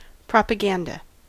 Ääntäminen
US : IPA : [ˌpɹɑp.ə.ˈɡæn.də]